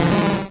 Cri de Tadmorv dans Pokémon Rouge et Bleu.